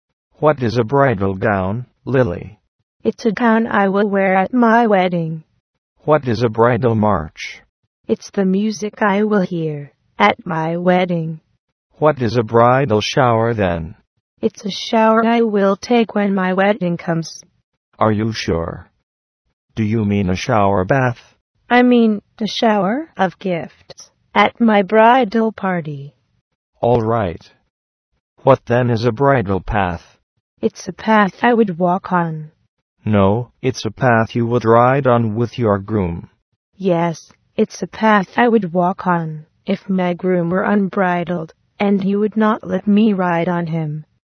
念音